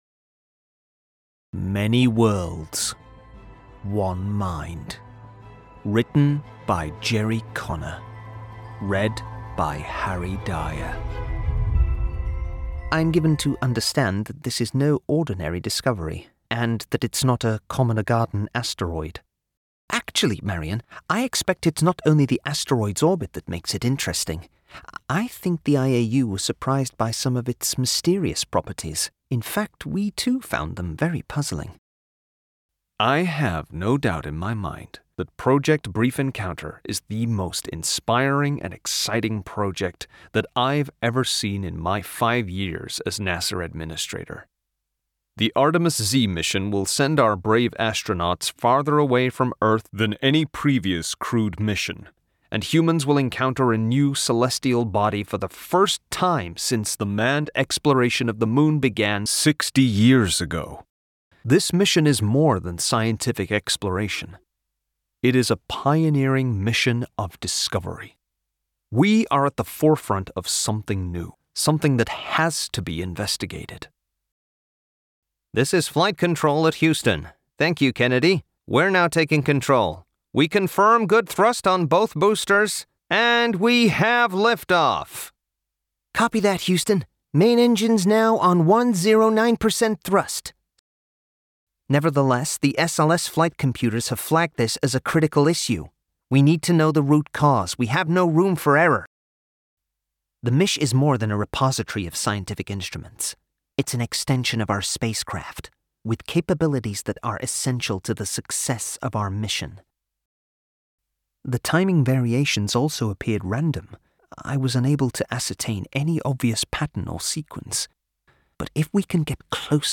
Audiobook Sample Listen to audiobook sample